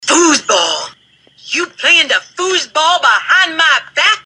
Tags: sports radio